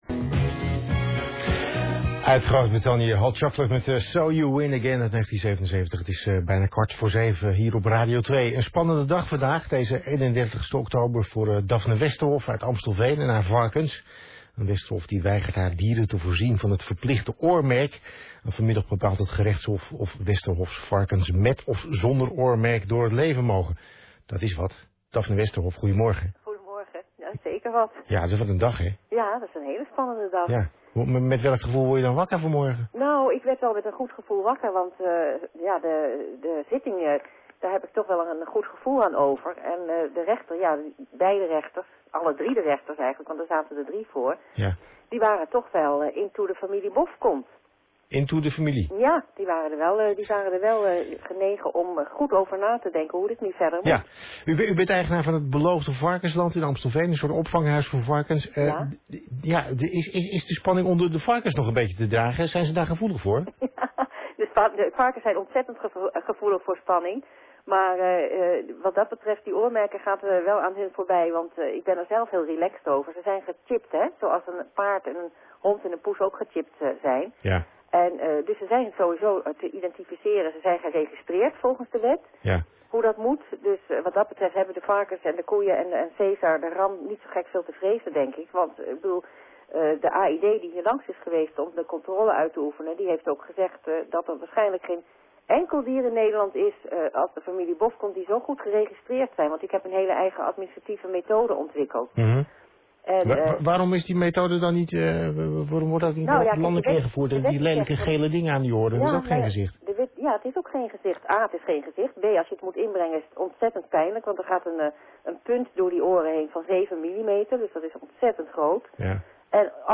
Zometeen word ik om 6.45 uur gebeld door VARA'S Ontbijtradio.